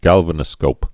(gălvə-nə-skōp, găl-vănə-)